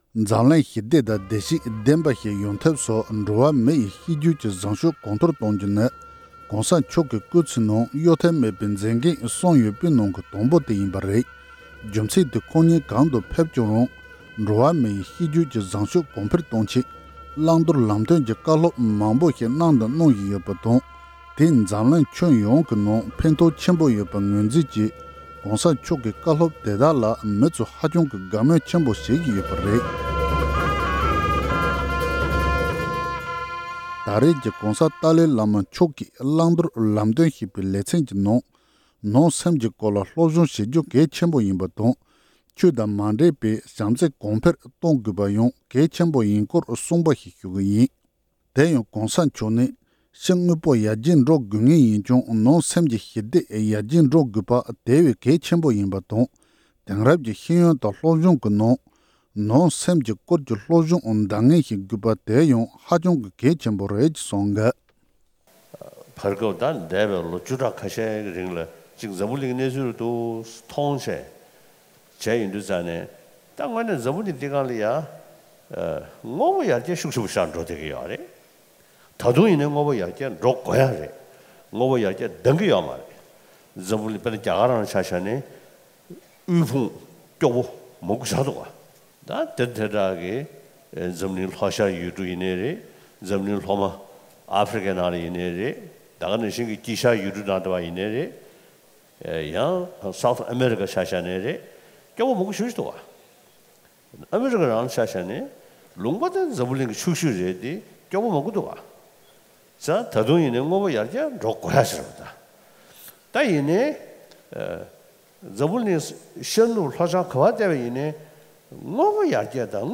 ༸གོང་ས་མཆོག་གིས་ནང་སེམས་ཀྱི་སྐོར་ལ་སློབ་སྦྱོང་བྱེད་རྒྱུ་གལ་ཆེན་པོ་ཡིན་པ་སོགས་ཀྱི་སྐོར་བཀའ་སློབ་བསྩལ་གནང་ཡོད་པ།